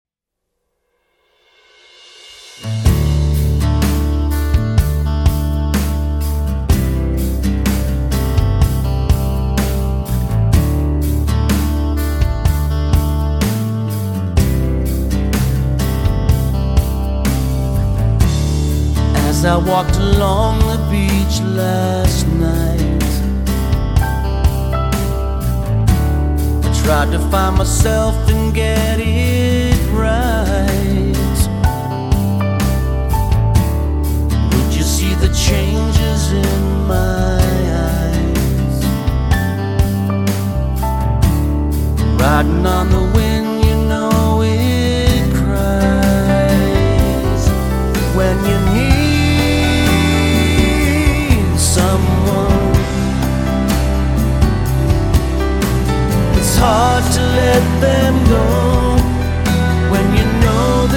Metal
Бескомпромиссный hard&heavy metal